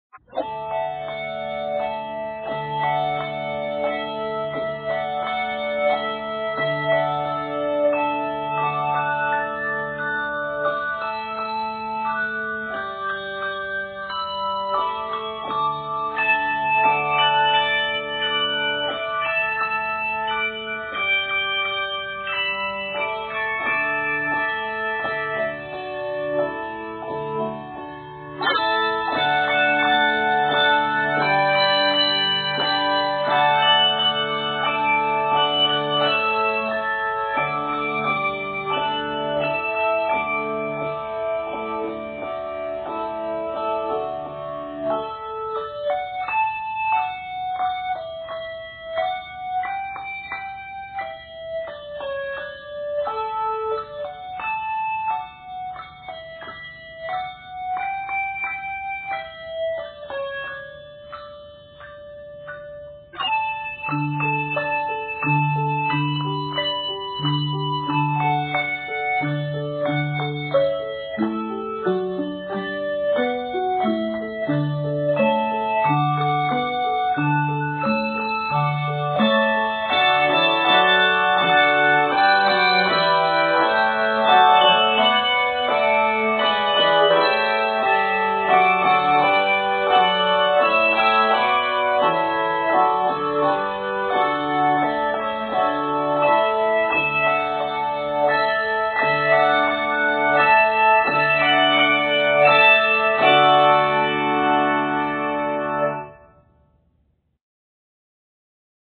an arrangement of a favorite hymn